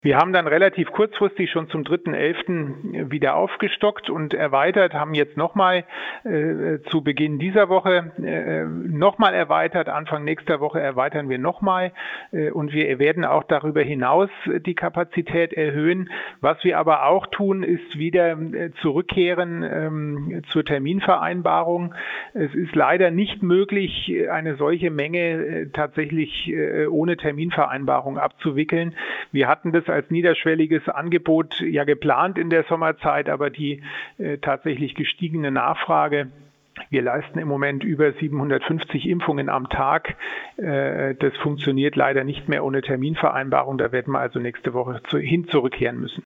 Impfkapazität, Boostern und 3G-Kontrollen: Schweinfurts Ordnungsreferent Jan von Lackum im Interview - PRIMATON